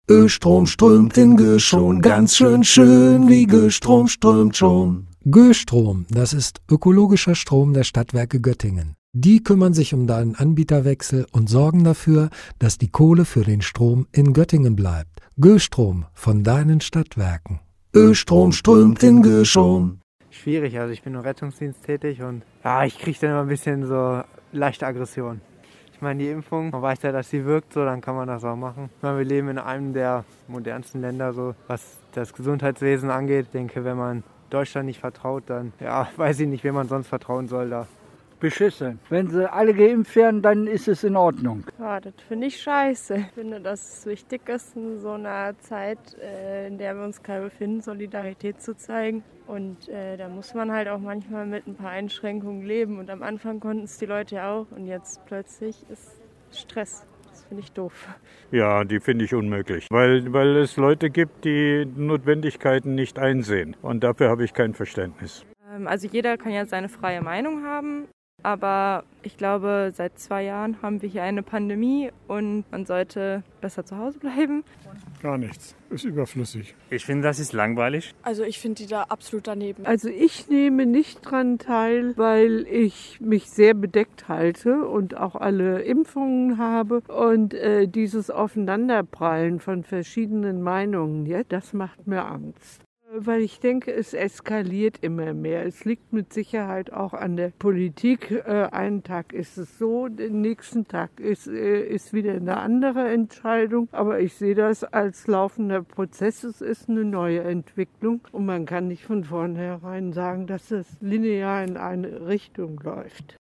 waren in der Göttinger Innenstadt unterwegs und haben mal nachgefragt, was die Göttinger Bürger von den Demos halten.